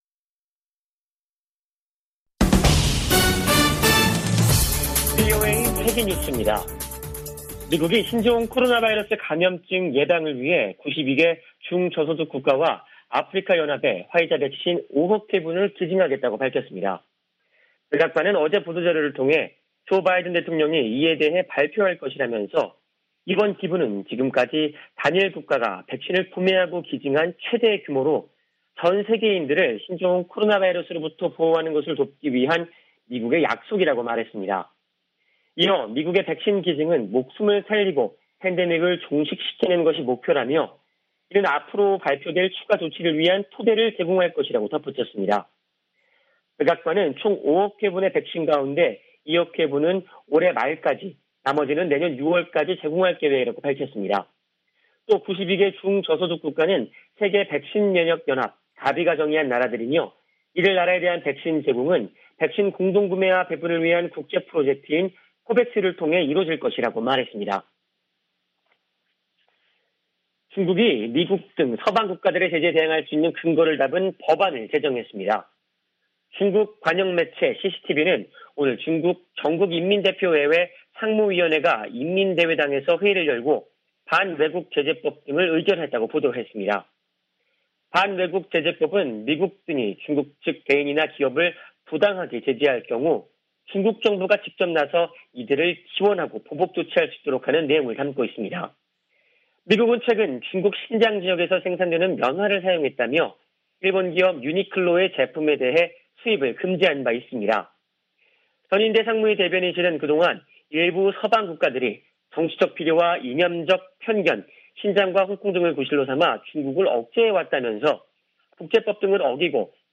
VOA 한국어 간판 뉴스 프로그램 '뉴스 투데이', 2부 방송입니다.